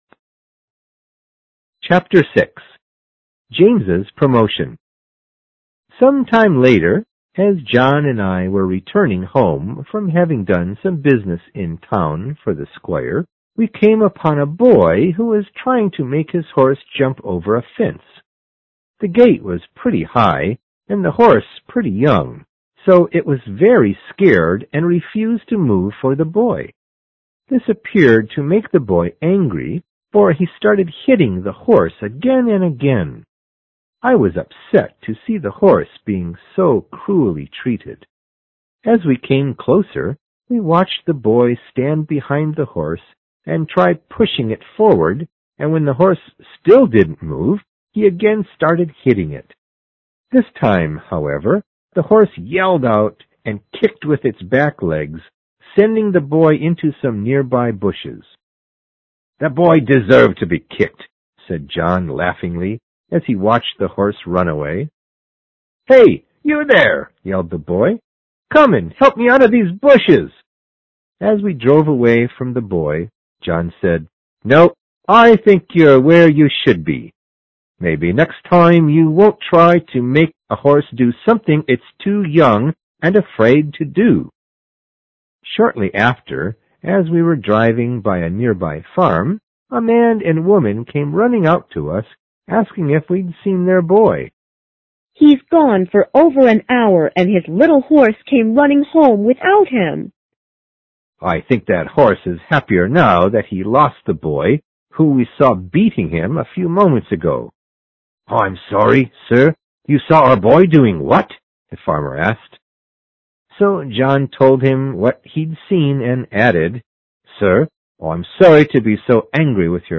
有声名著之黑骏马06 听力文件下载—在线英语听力室